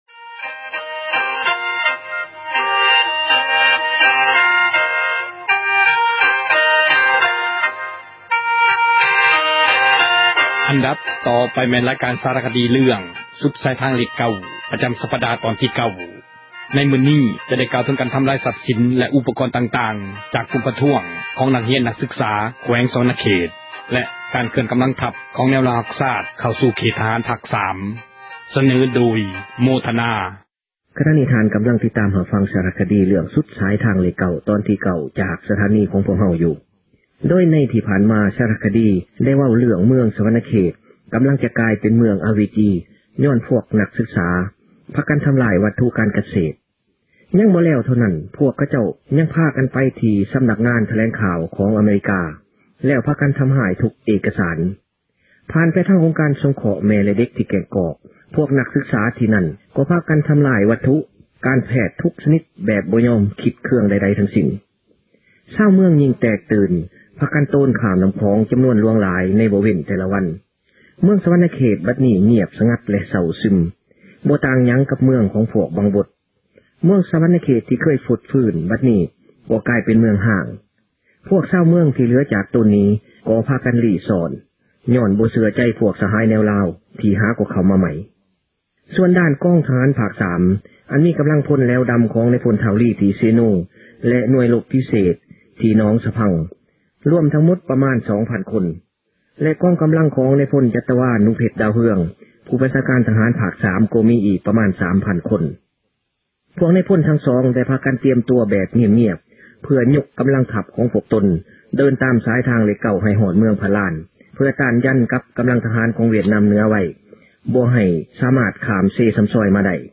ຣາຍການ ສາຣະຄະດີ ເຣື້ອງ ”ສຸດສາຍທາງເລຂ 9” ປະຈຳສັປດາ ຕອນທີ 9 ໃນມື້ນີ້ ຈະໄດ້ກ່າວເຖິງ ການທຳລາຍຊັບສີນ ແລະອຸປກອນຕ່າງໆ ໂດຍກຸ່ມປະທ້ວງ ຊື່ງແມ່ນນັກຮຽນ ນັກສືກສາ ແຂວງສວັນນະເຂດ ແລະ ການເຄື່ອນກຳລັງທັບ ຂອງແນວລາວຮັກຊາດ ເຂົ້າສູ່ເຂດ ທະຫານພາກສາມ.